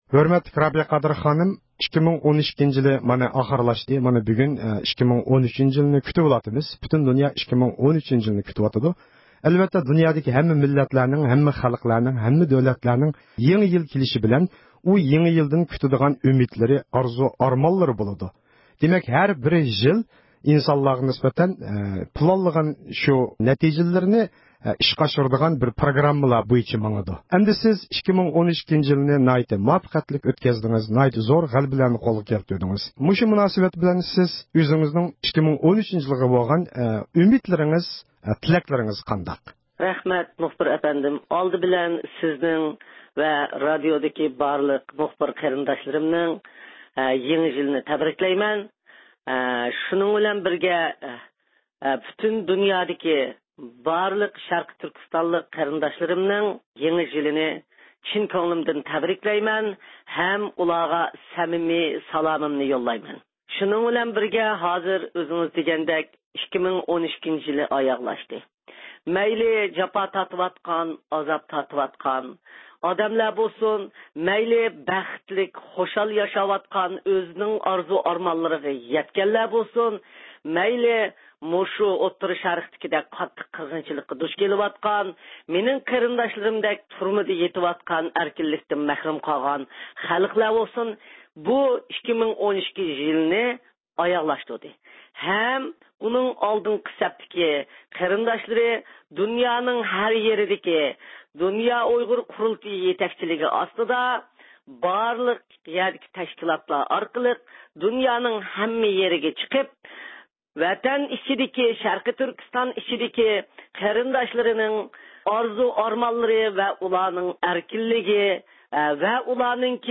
ئۇيغۇر مىللىي ھەرىكىتىنىڭ رەھبىرى رابىيە قادىر خانىم 2012-يىلىنىڭ ئاخىرلىشىپ، 2013-يىلىنى كۈتۈۋېلىش مۇناسىۋىتى بىلەن پۈتۈن ئۇيغۇر خەلقىنى يېڭى يىل بىلەن تەبرىكلىدى ھەمدە ئۆزىنىڭ يېڭى بىر يىلدىكى ئۈمىد-ئارزۇلىرىنى ئىپادە قىلدى.